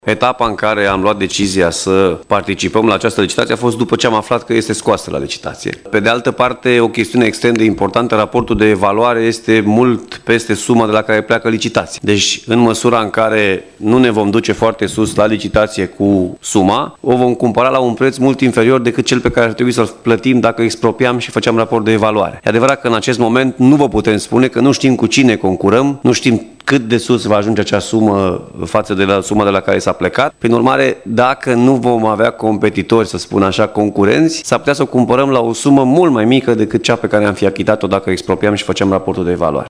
Consilierul Alin Nica a întrebat în plen care a fost motivul pentru care baza nu a fost expropriată, având în vedere datoriile acumulate, și de ce s-a preferat participarea la licitație. Răspunsul a venit din partea lui Alfred Simonis, președintele Consiliului Județean Timiș.